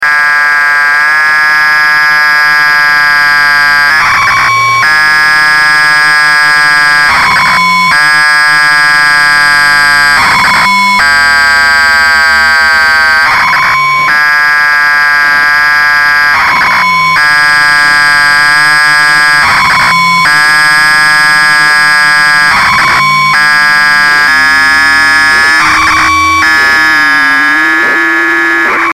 DELFI C3 Satellite (telemetria 145,930 Mhz) DO-64